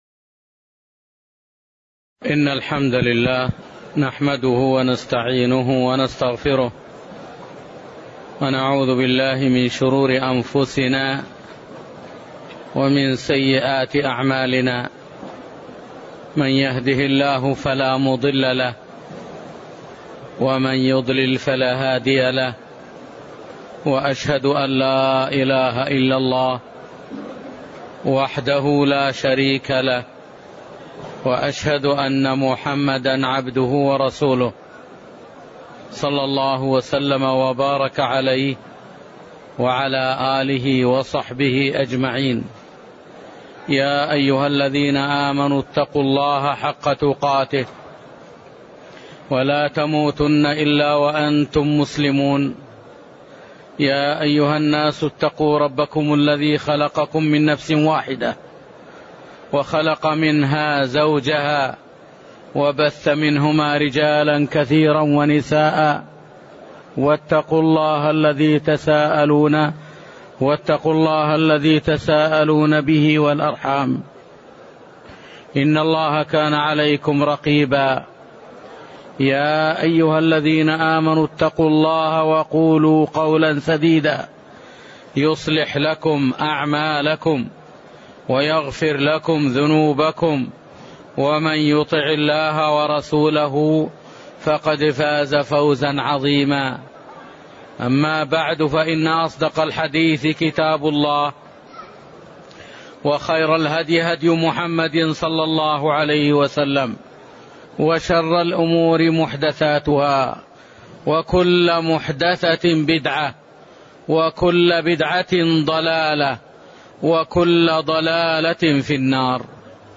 تاريخ النشر ٤ رمضان ١٤٣٠ هـ المكان: المسجد النبوي الشيخ